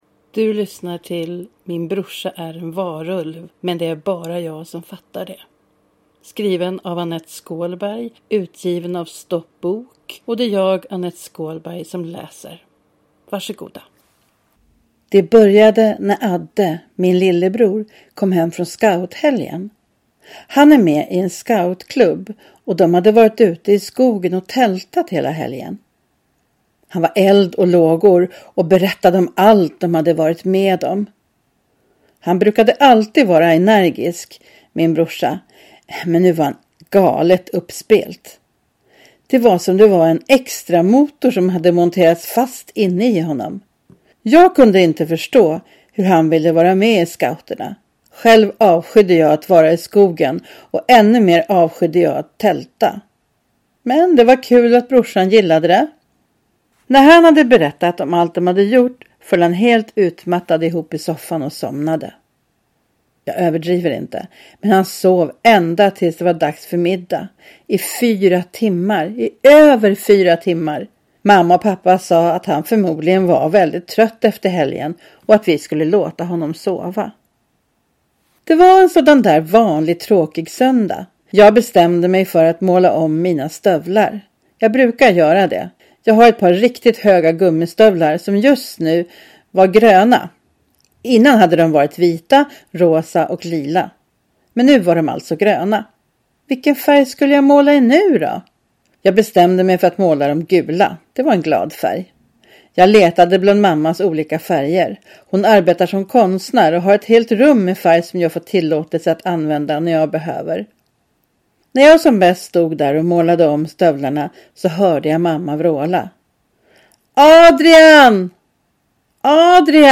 Min brorsa är en varulv - men det är bara jag som fattar det – Ljudbok